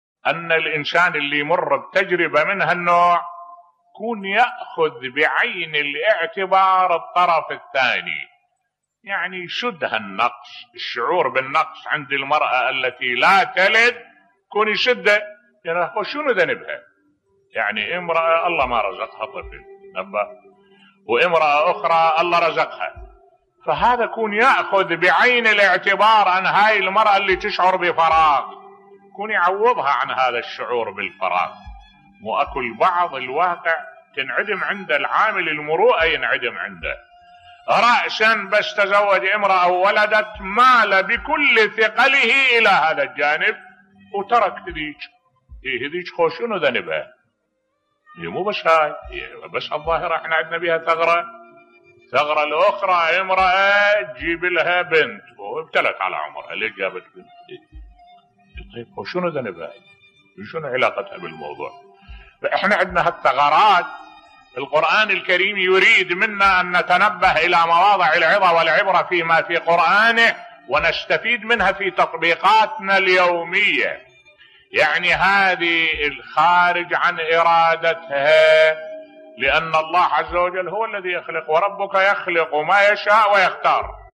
ملف صوتی على الزوج أن يراعي نفسية زوجته عند عدم قدرتها على الانجاب بصوت الشيخ الدكتور أحمد الوائلي